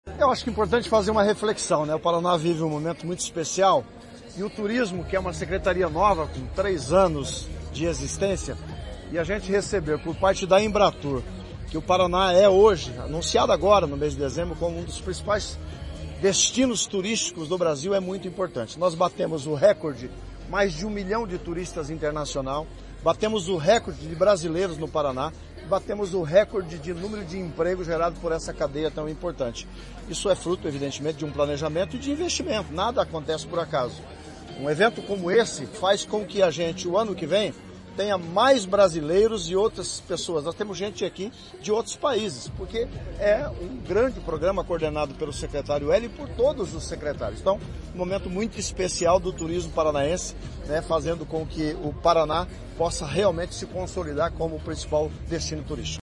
Sonora do secretário do Turismo, Leonaldo Paranhos, sobre o primeiro fim de semana de shows do Verão Maior Paraná